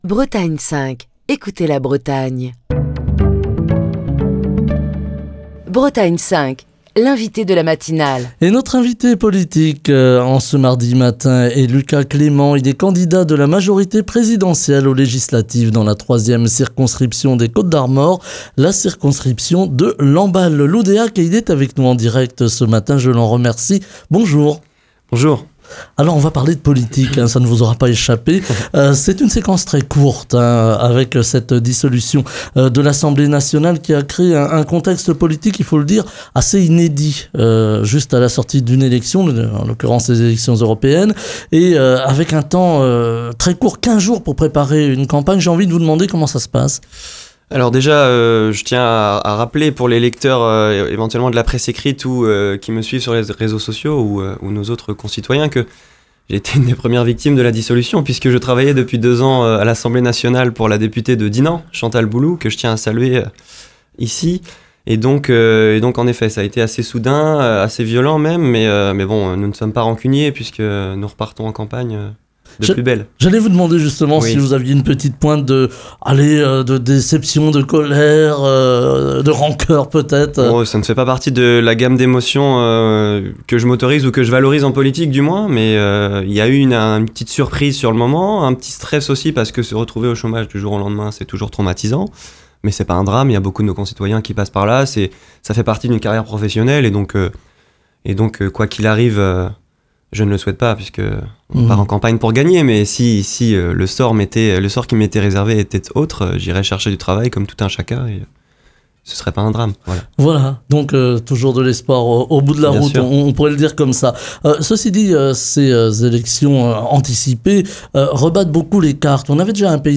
Émission du 25 juin 2024.